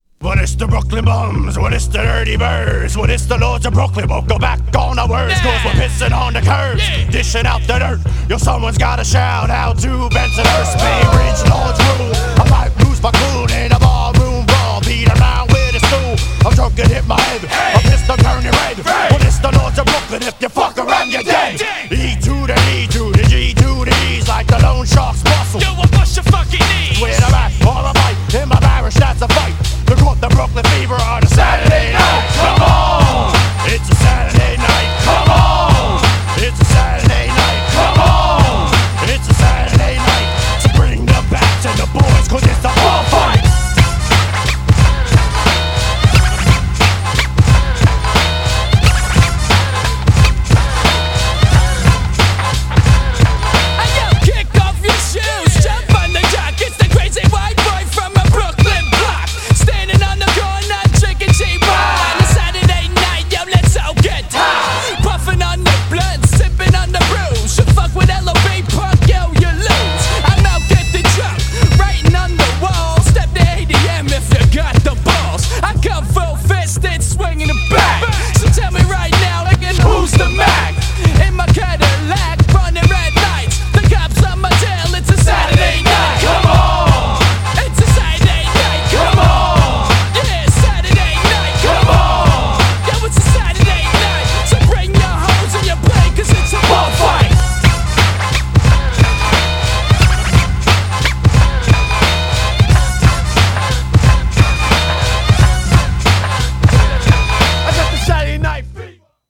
GENRE Hip Hop
BPM 91〜95BPM